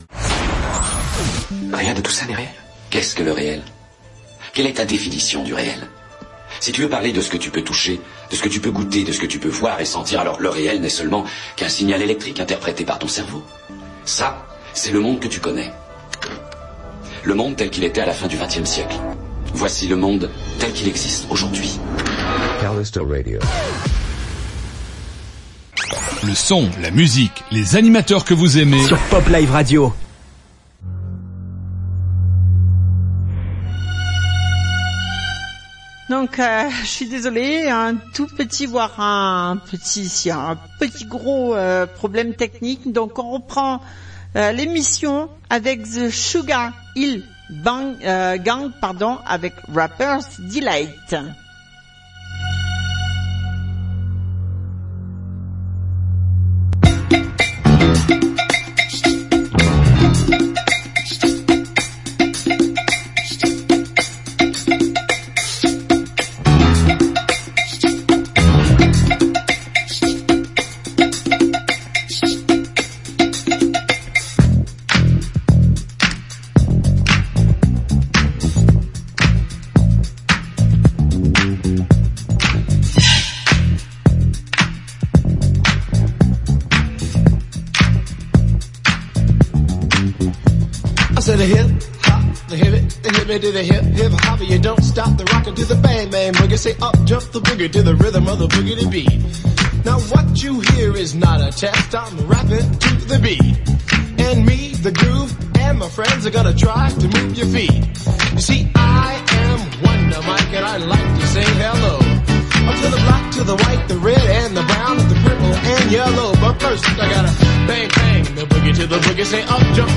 Rap Rnb Histoire du rap.
Rap-Rnb-Histoire-du-rap.mp3